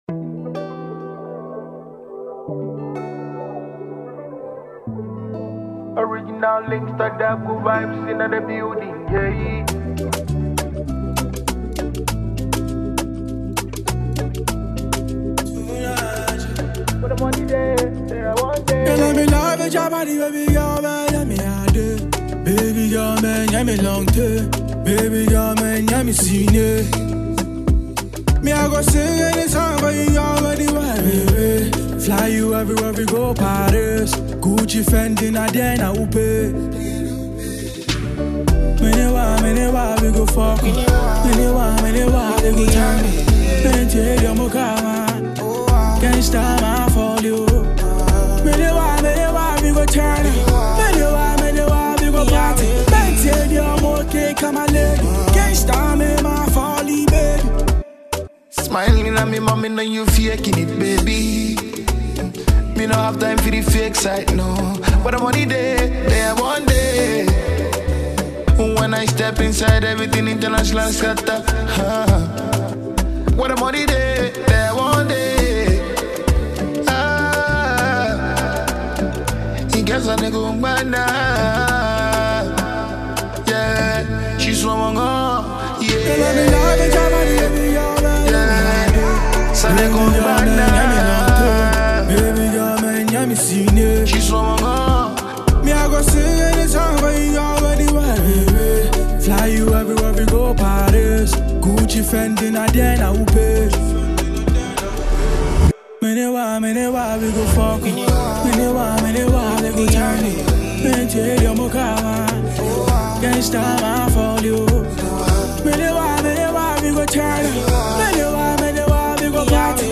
Genre: Afrobeat
catchy rhythms and afrobeat vibes